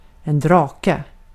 Ääntäminen
IPA : /bə.ˈlɪdʒ.(ə).ɹənt/